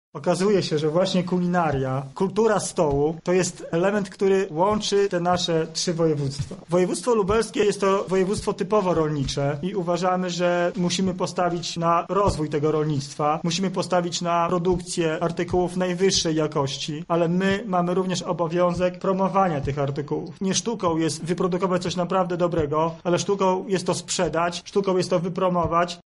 – mówi Sebastian Trojak, Członek Zarządu Województwa Lubelskiego.